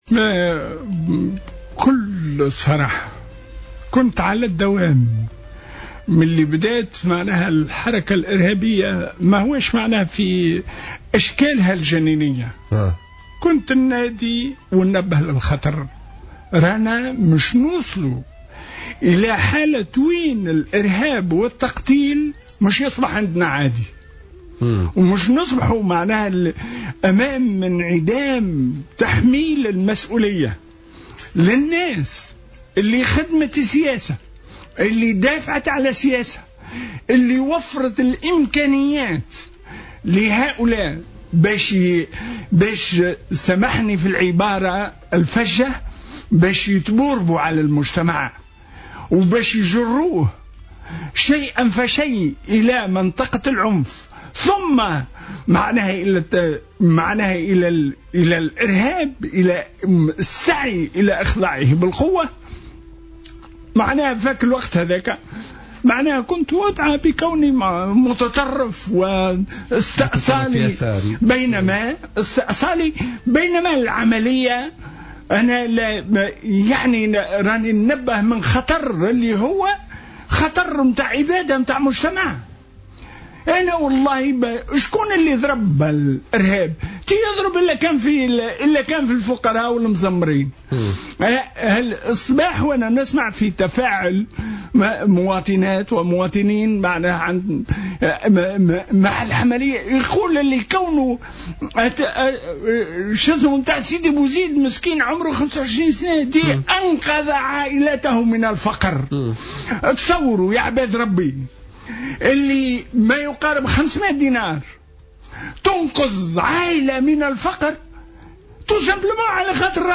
حمّل أمين عام الحزب الاشتراكي، محمد الكيلاني ضيف برنامج بوليتيكا اليوم الأربعاء حكومة الترويكا المسؤولية السياسية لكل ما يحدث من عمليات إرهابية في تونس.